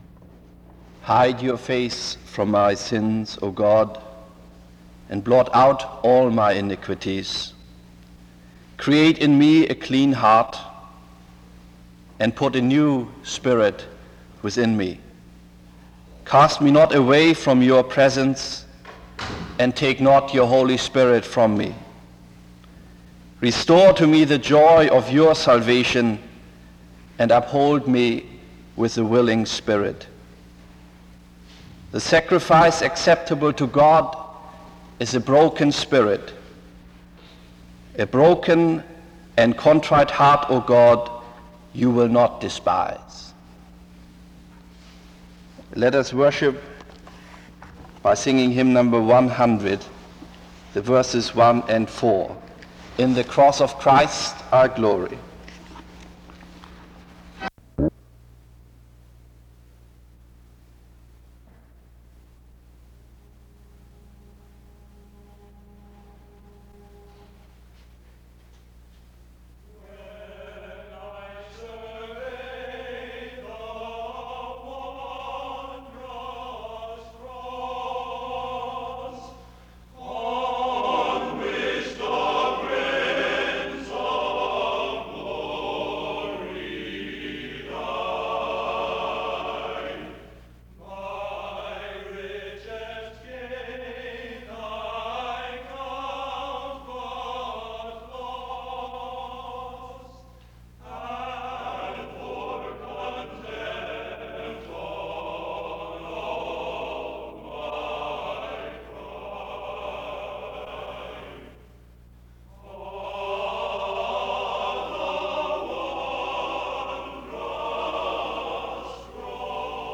The service begins with a scripture reading (0:00-1:00). After which, there is a time of singing (1:01-4:50). The service continues with a period of prayer (4:51-6:53).
The service closes in prayer (21:19-21:35).